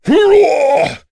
Kaulah-Vox_Attack5.wav